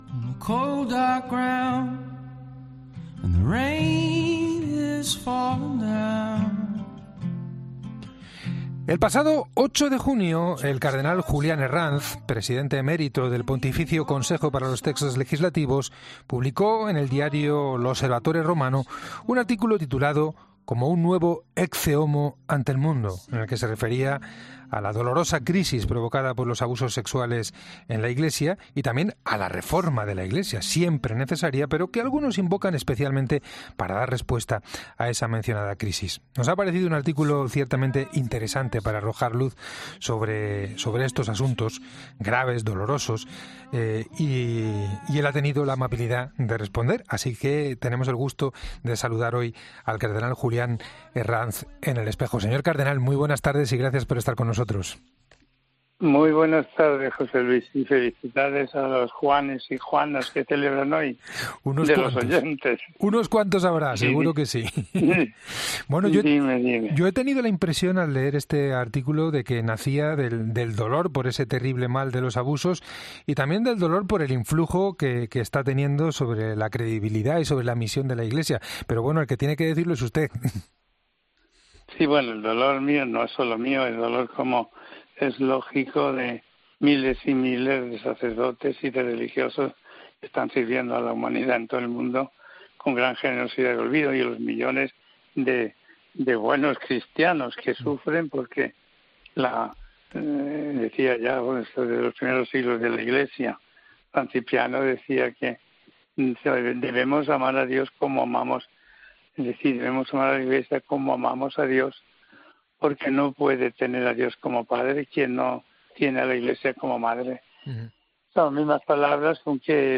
El cardenal ha compartido algunas ideas con los oyentes de El Espejo de la Cadena COPE.